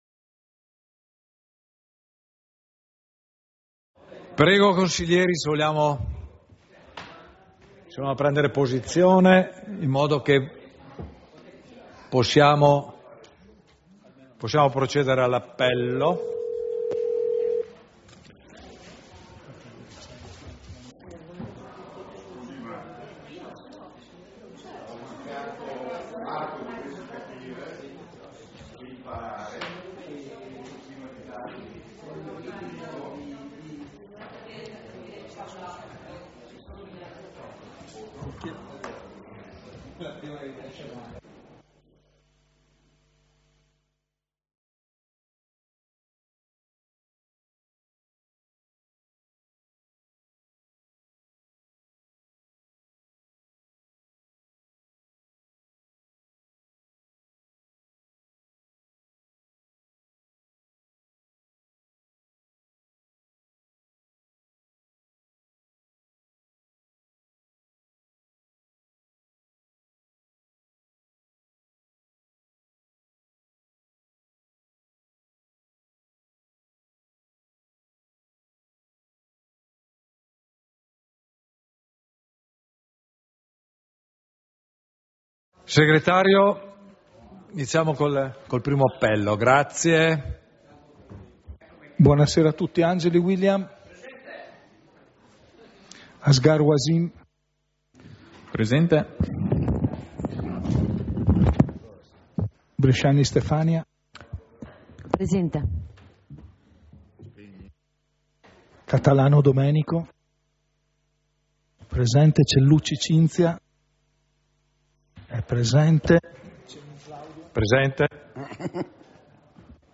Seduta del consiglio comunale - 25.11.2025